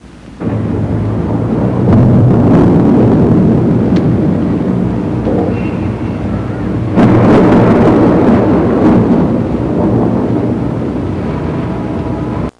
Thunder In The Distance Sound Effect
Download a high-quality thunder in the distance sound effect.
thunder-in-the-distance.mp3